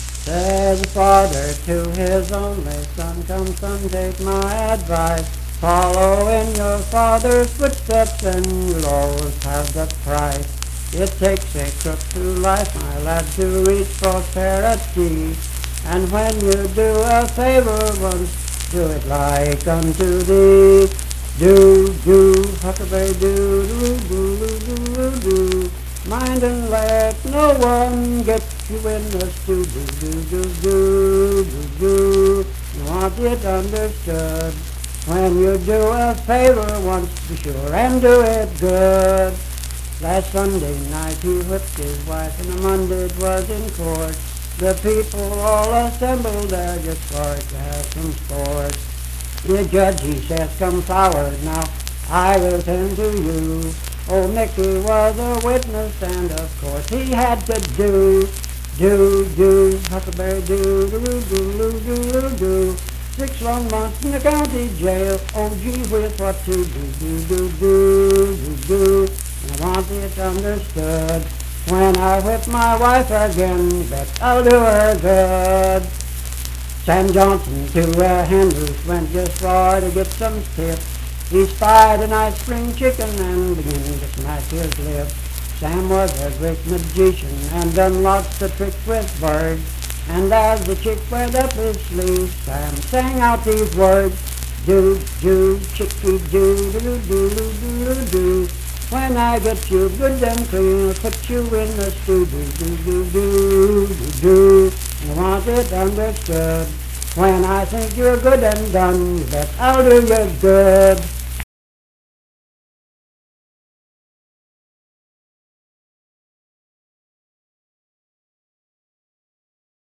Unaccompanied vocal performance
Voice (sung)
Harrison County (W. Va.)